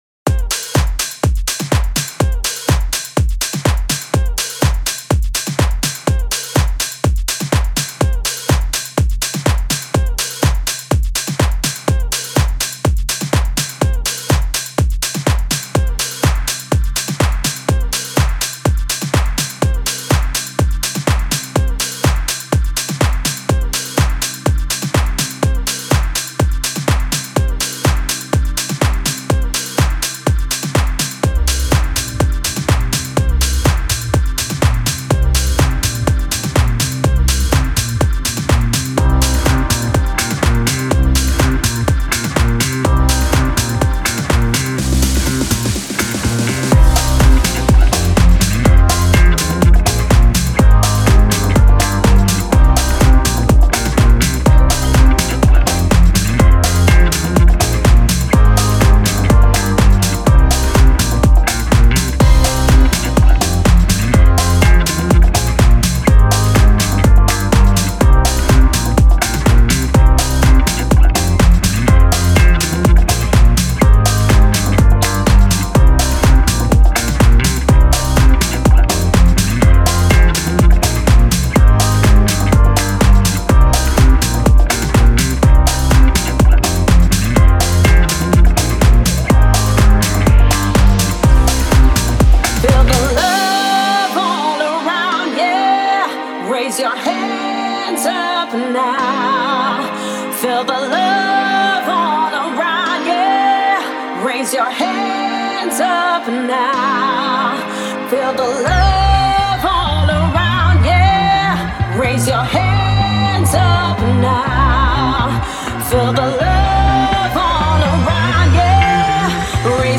5A - 124 Nu Disco